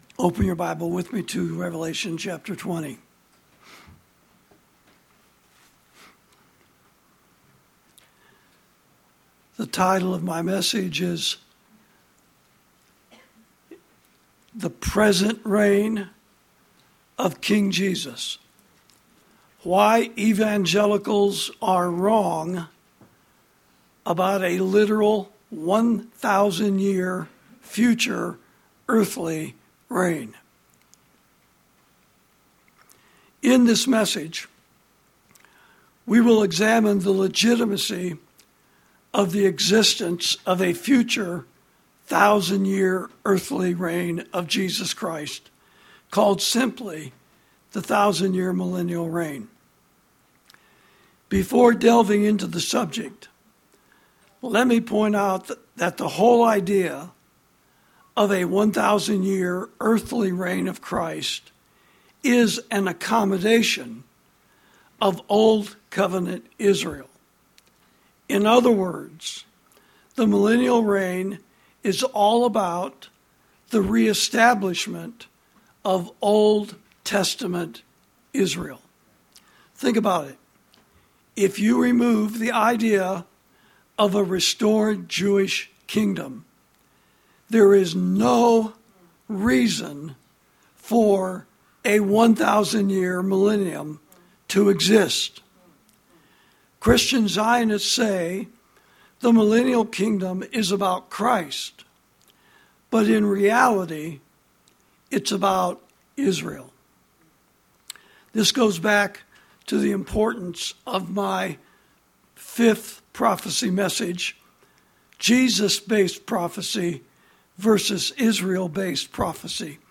Sermons > The Present Reign Of King Jesus: Why Evangelicals Are Wrong About A Literal One-Thousand-Year Future Earthly Reign (Prophecy Message Number Twenty-Four)